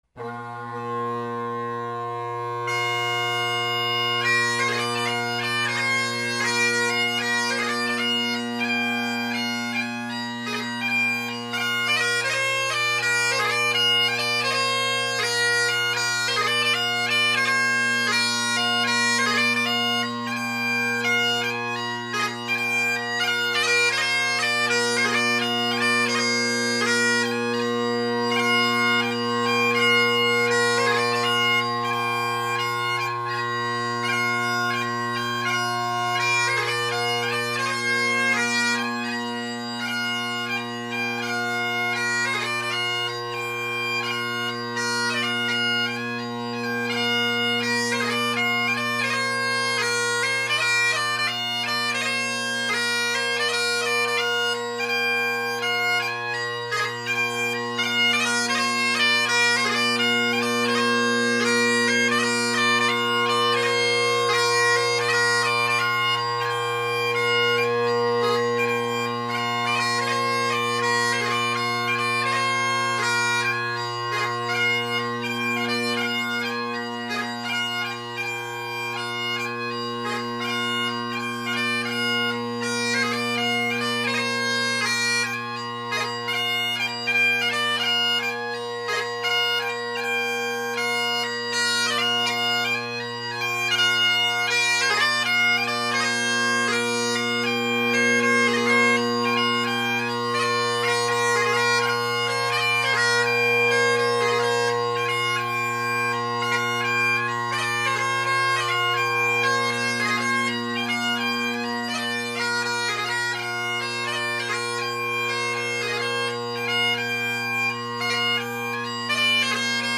Robertson bagpipes with Robertson Rocket drone reeds
Drone Sounds of the GHB
The bass is big and the tenors mellow and with where I put the recorder (same spot as usual) you get a lot of bass without much tenor. There are spots in the recordings where you can hear the blend as for the most part I’m usually just meandering around the room, so they’re there, you’ll just have to wait for them.
Leaving Port Askaig, Angus MacKinnon, and Ellenor – A few band tunes out of order and in the wrong sets